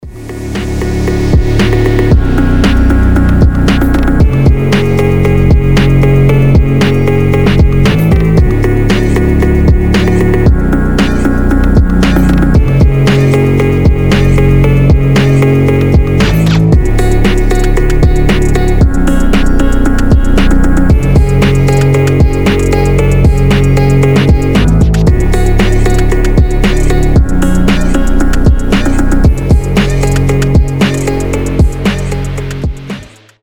басы
качающие
фонк
Приятный минимал фонк